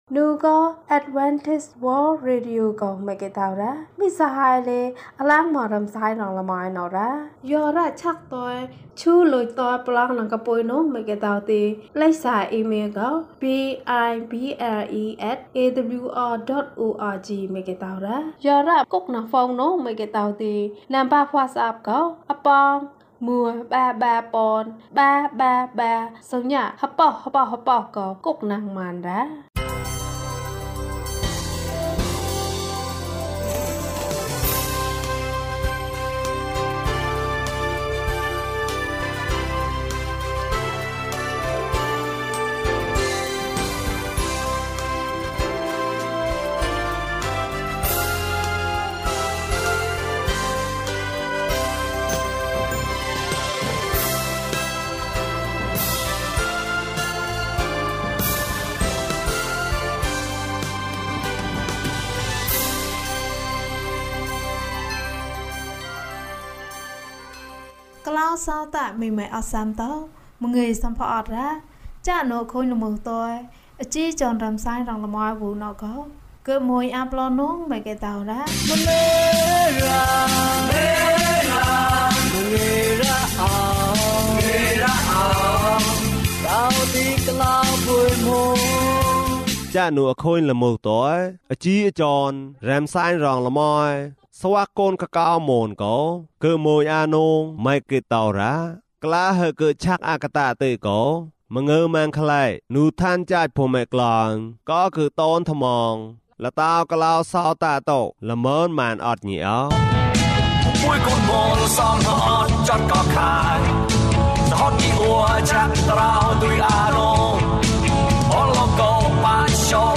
ငါ့ထံမှ ဘုရားသခင် ကောင်းကြီးပေးပါစေ။ ကျန်းမာခြင်းအကြောင်းအရာ။ ဓမ္မသီချင်း။ တရားဒေသနာ။